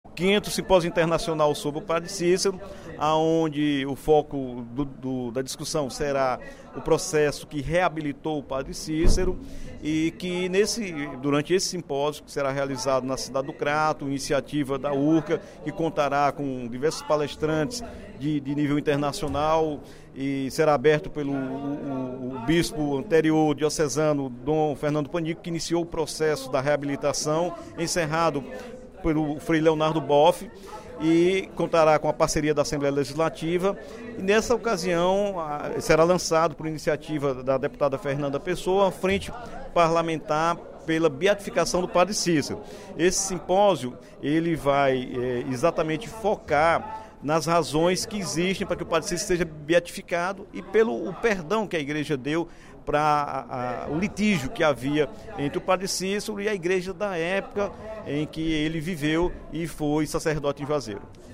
O deputado Dr. Santana (PT) anunciou, durante o primeiro expediente da sessão plenária desta quinta-feira (23/02), a realização do V Seminário Internacional sobre Padre Cícero, de 20 a 24 de março, no Crato.